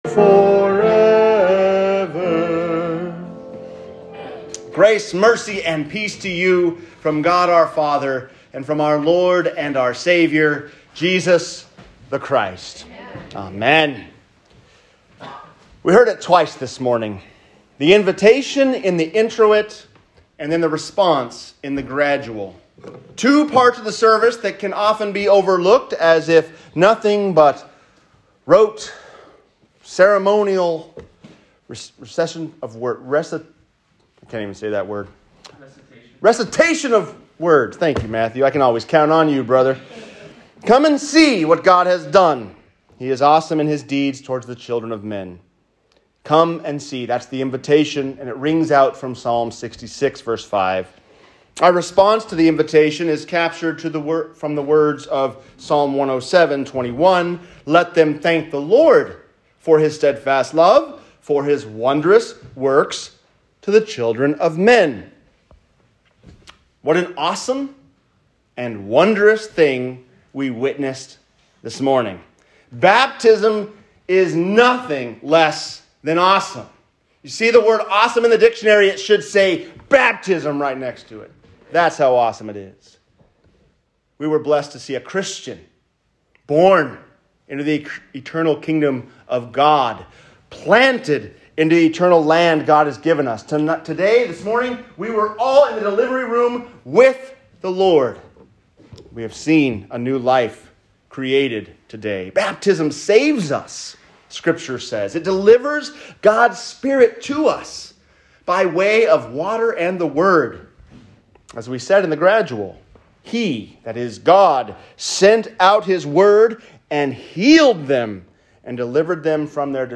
God Does Awesome Deeds To the Children of Men | Sermon